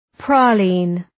Προφορά
{‘prɒlın}